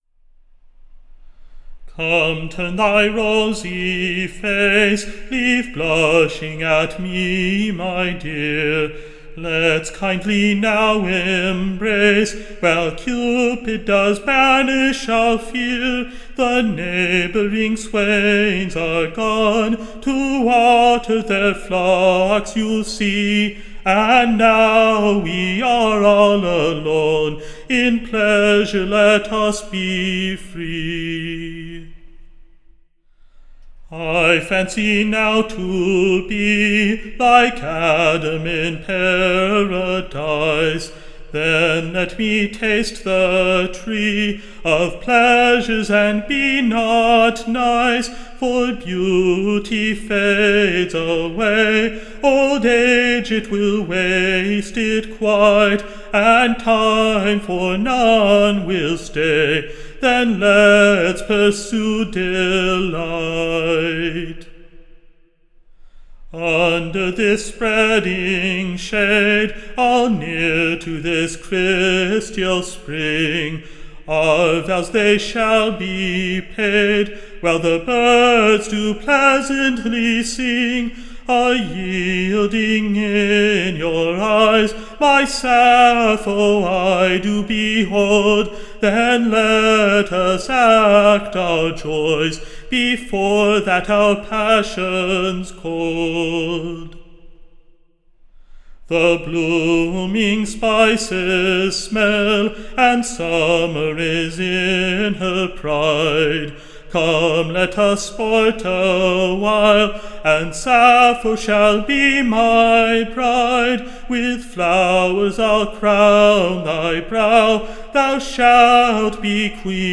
Tune Imprint A pleasant New Play-house Song, To the Tune of, Hail to the Myrtle Shades.